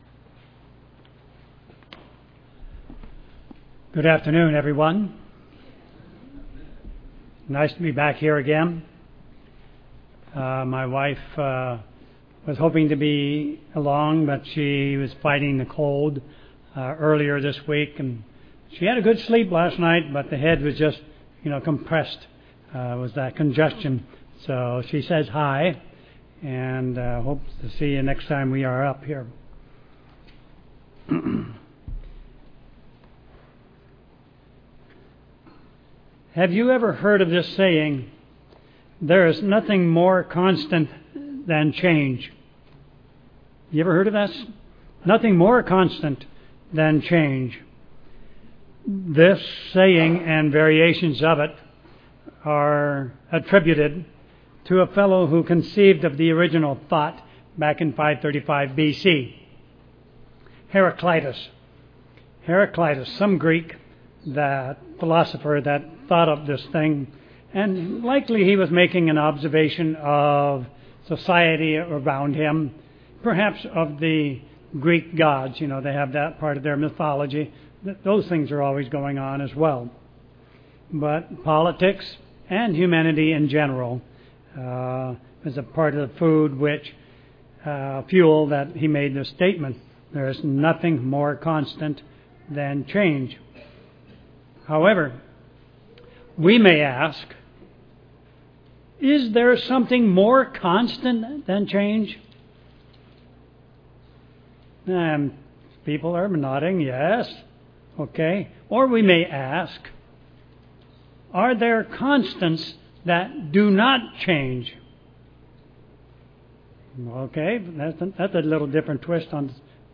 Given in Tacoma, WA
UCG Sermon Studying the bible?